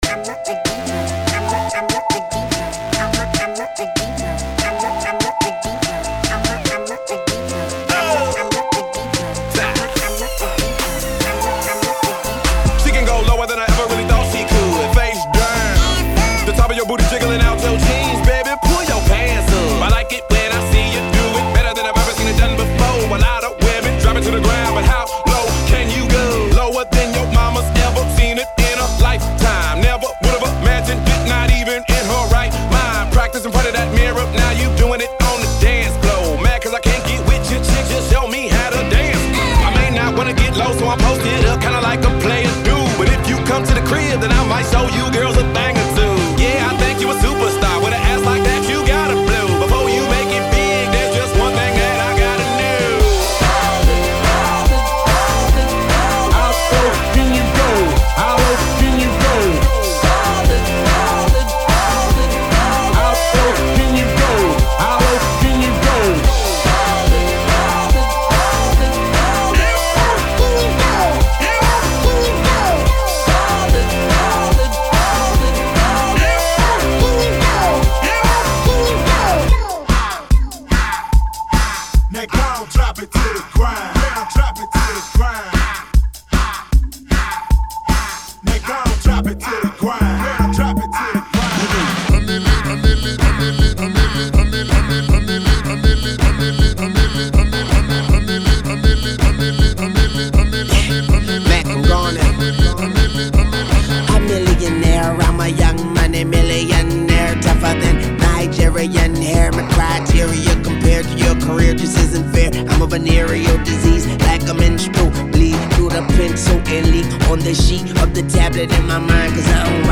Is it danceable? Not exactly.